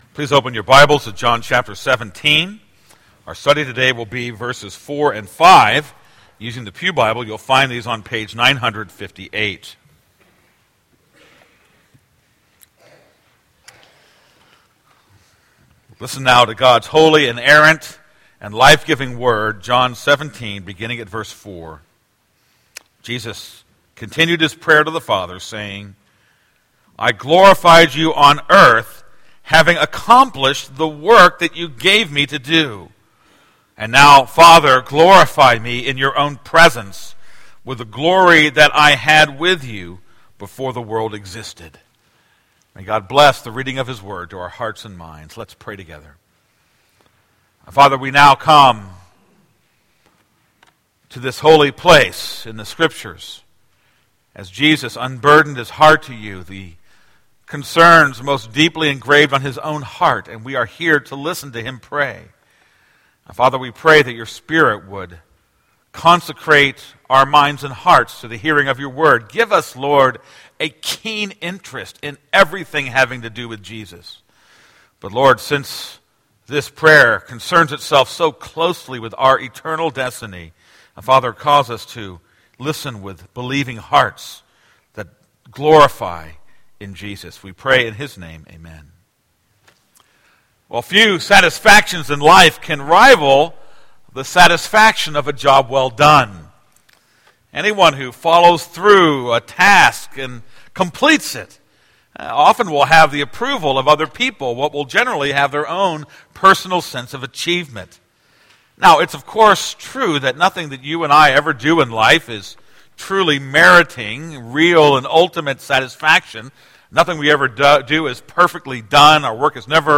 This is a sermon on John 17:4-5.